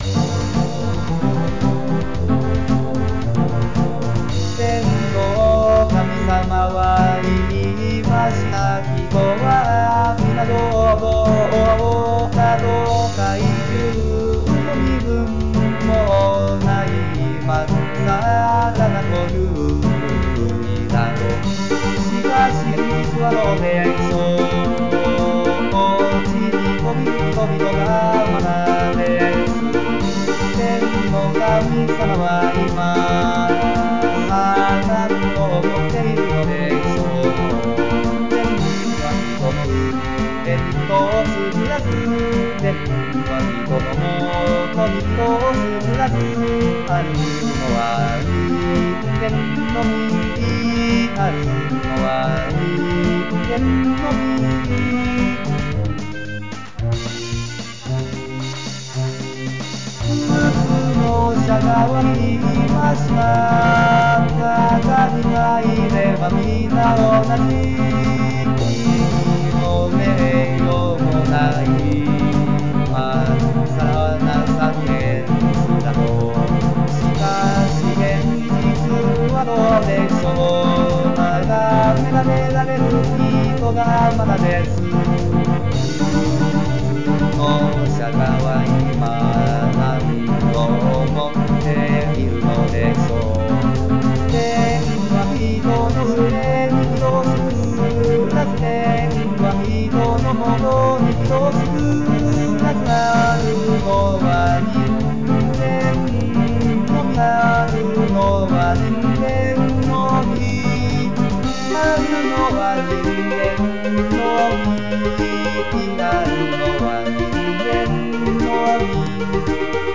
日本語歌詞から作曲し、伴奏つき合成音声で歌います。